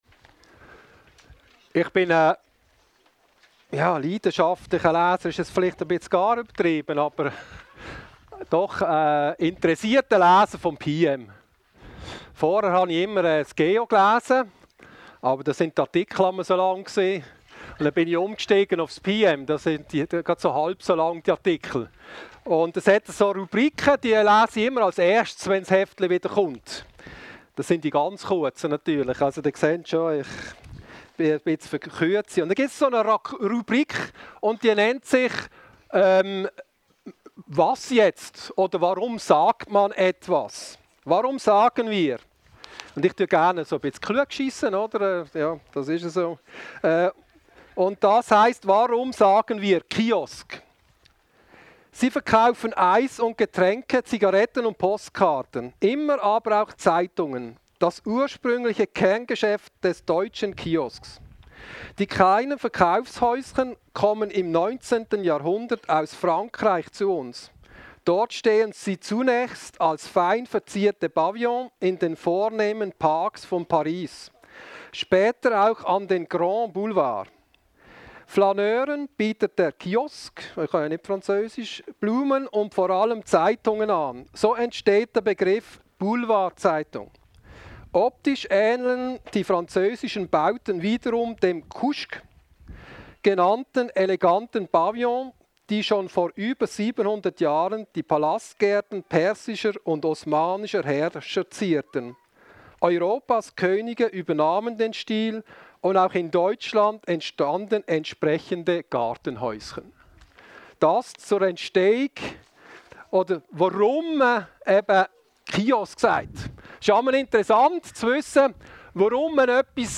Taufgottesdienst
02.-juli-taufgottesdienst-werdhof.mp3